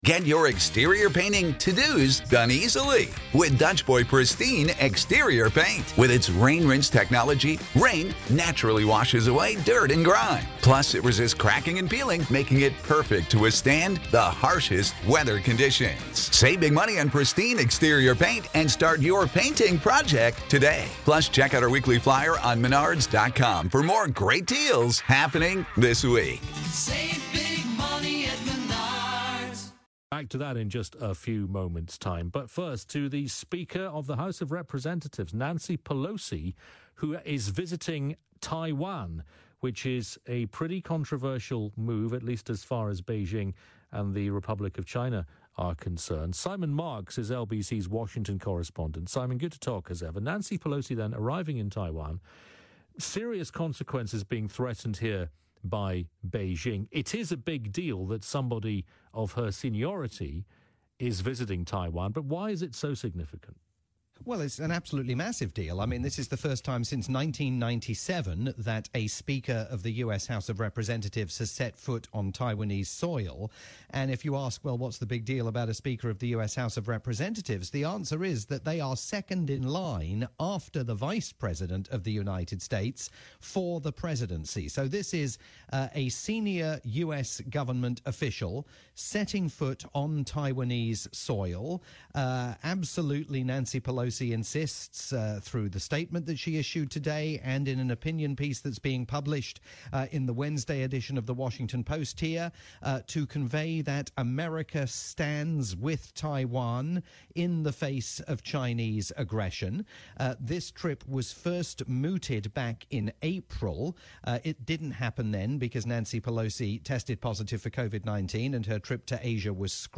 overnight programme on the UK's LBC